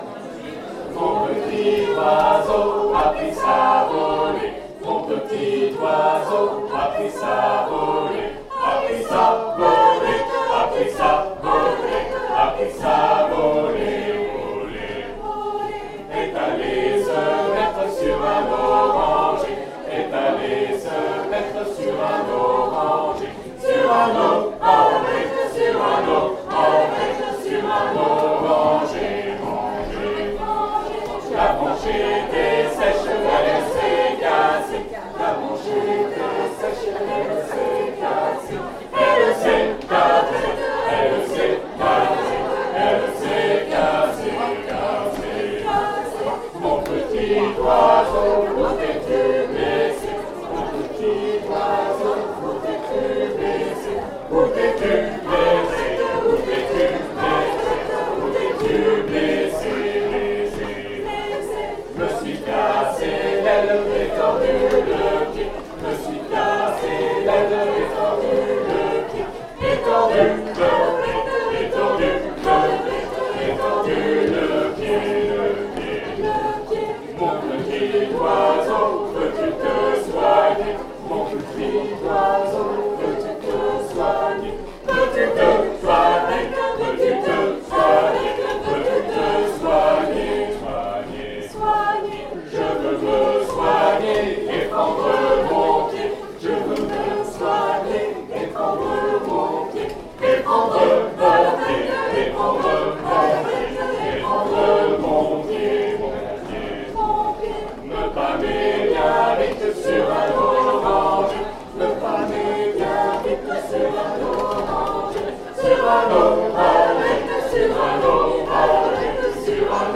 Chanson du répertoire traditionnel
Polka piquée (bourrée 2 temps / chapelloise)
arrangement SATB
Par le C(h)œur à danser lors d’un bal